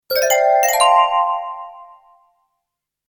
Shiny Crystal Accent Sound Effect
Description: Shiny crystal accent sound effect. Experience a bright and sparkling crystal notification with clear, shiny tones. Perfect for intros, transitions, games, and videos that need a clean and polished sound.
Shiny-crystal-accent-sound-effect.mp3